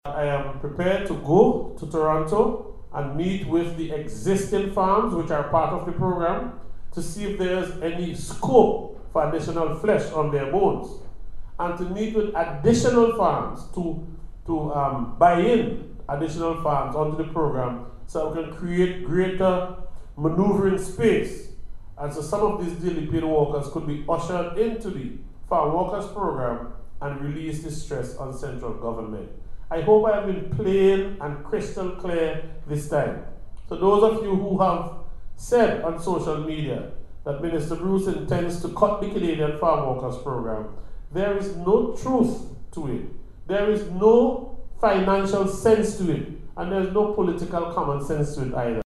Speaking at a recent consultation hosted by Attorney General Hon. Louise Mitchell, Minister Bruce said he plans to travel to Canadian farms to secure additional contracts for Vincentians.